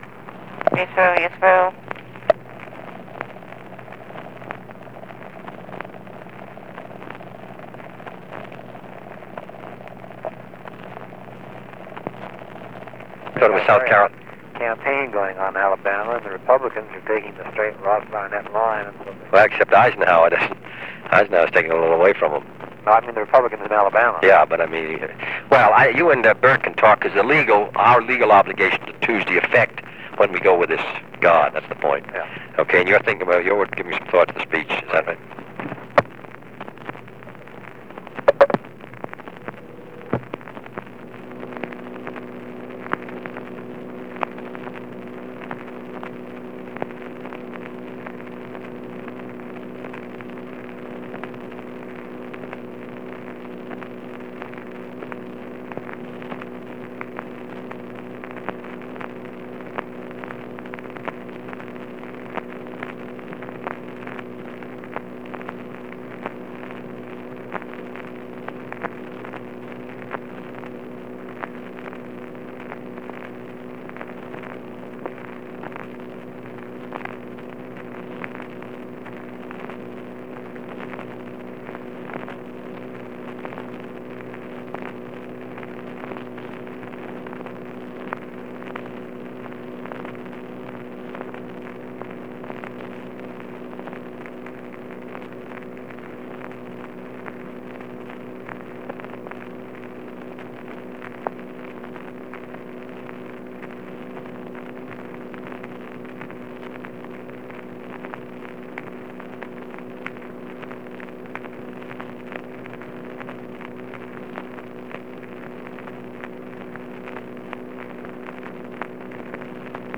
Conversation with Theodore Sorensen
Secret White House Tapes | John F. Kennedy Presidency Conversation with Theodore Sorensen Rewind 10 seconds Play/Pause Fast-forward 10 seconds 0:00 Download audio Previous Meetings: Tape 121/A57.